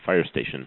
fire.mp3